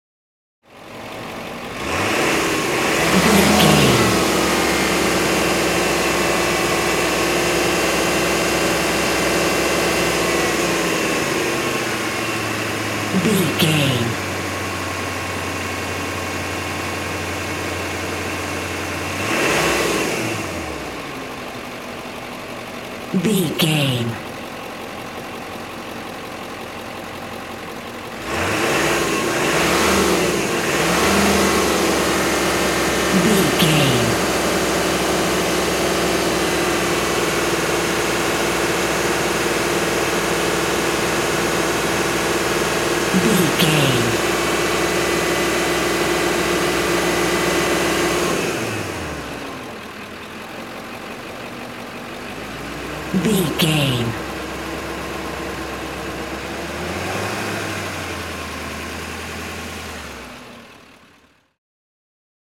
Ambulance Ext Diesel Engine Accelerate
Sound Effects
urban
chaotic
emergency